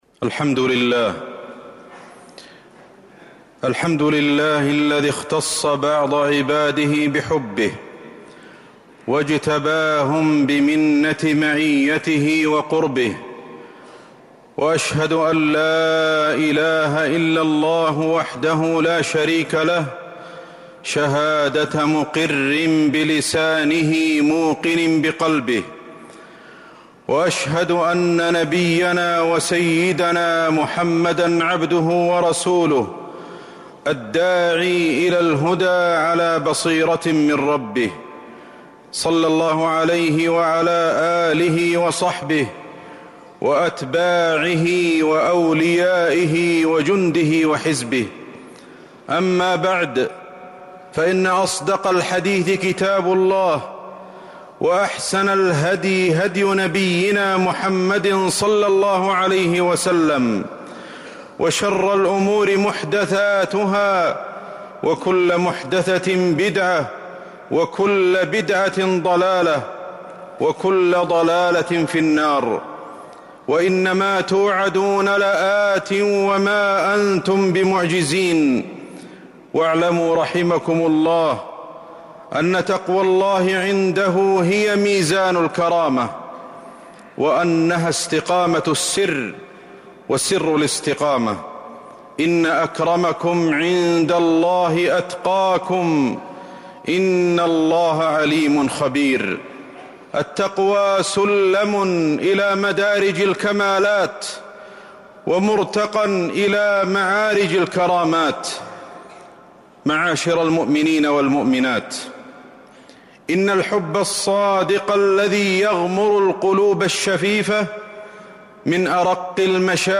جودة عالية